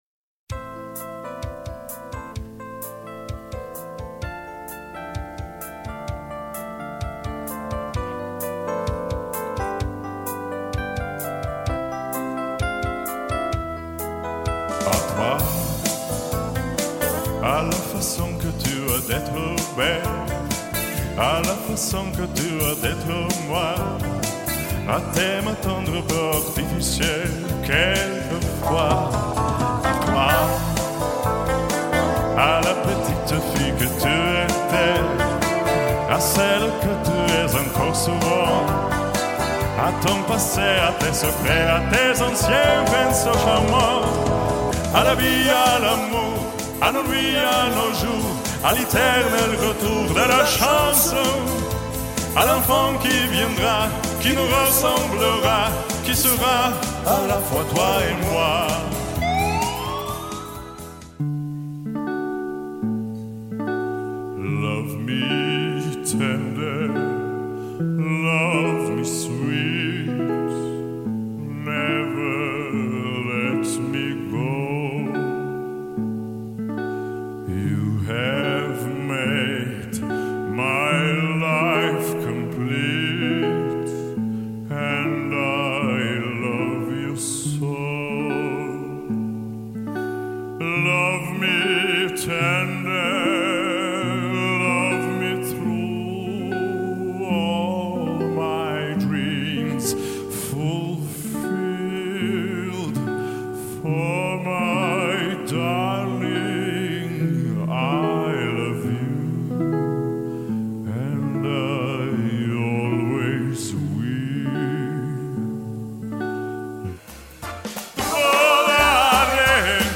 Попурри из мировых хитов.mp3